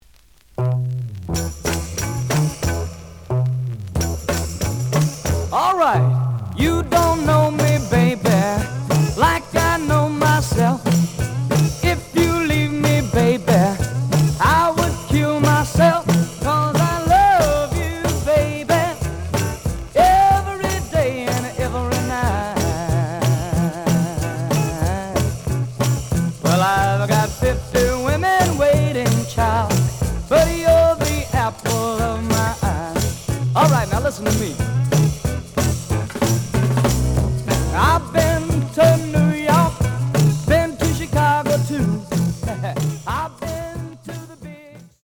試聴は実際のレコードから録音しています。
●Genre: Rhythm And Blues / Rock 'n' Roll
●Record Grading: VG+ (両面のラベルに若干のダメージ。盤に歪み。多少の傷はあるが、おおむね良好。)